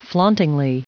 Prononciation du mot flauntingly en anglais (fichier audio)
Prononciation du mot : flauntingly